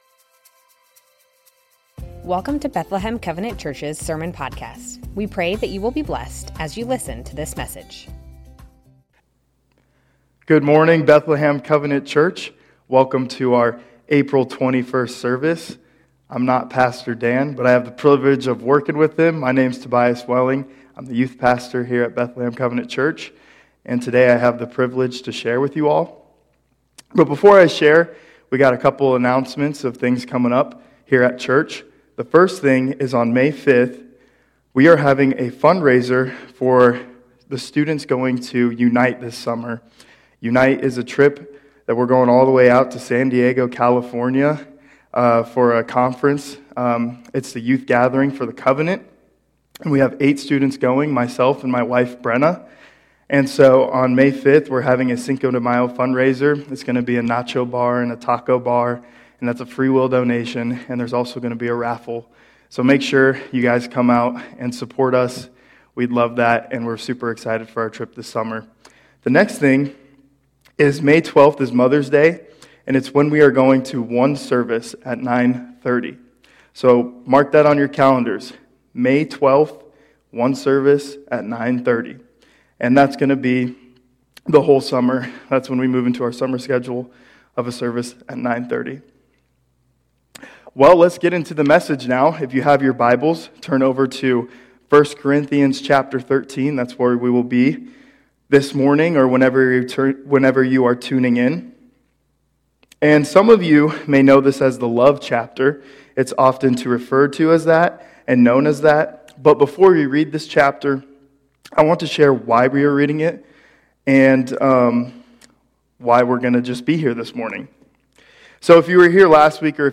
Bethlehem Covenant Church Sermons What is Love Apr 21 2024 | 00:33:26 1x 00:00 / 00:33:26 Subscribe Share Spotify RSS Feed Share Link Embed